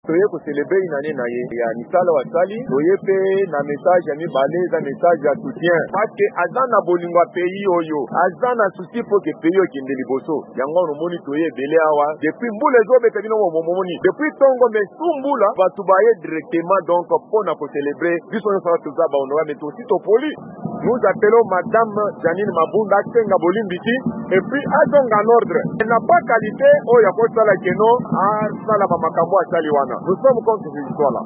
Bato bayike basanganaki na Lubumbashi, na nse ya mpela, mpo na kokanisa mobu moko ya bokoti na bokonzi ya Félix Tshisekedi. Balobi basepeli na mosala mosalami mpe bakebisi mokambi ya Assemblée nationale Jeanine Mabunda na maloba ya kotelemela bokweyisami ya parlement M. Tshisekedi alaki soki nkwokoso ezali.